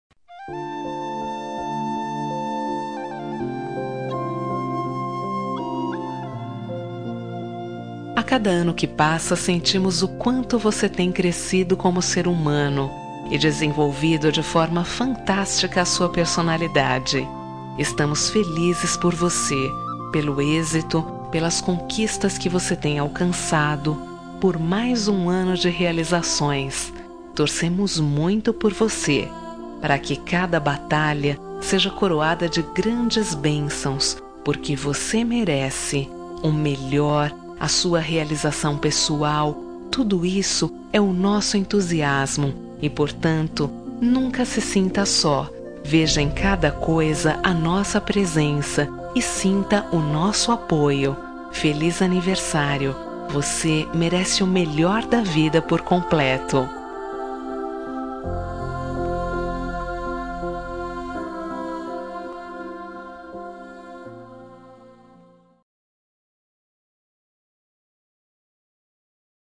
Telemensagem de Aniversário de Filho – Voz Feminina – Cód: 1820